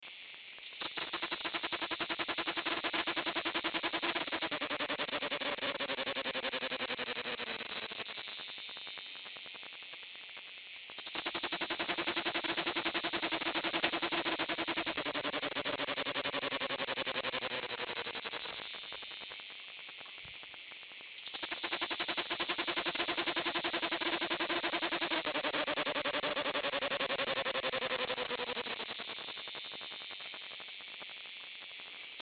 Cigale plébéienne Lyristes plebejus
[mp3] ATTENTION, il y a des grosses déformations ou autre phénomène sonore qui déforme l'enregistrement, plusieurs fois au cours de l'écoute (13/06/2017)